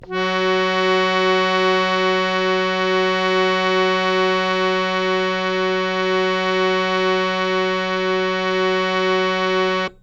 interactive-fretboard / samples / harmonium / Fs3.wav
Fs3.wav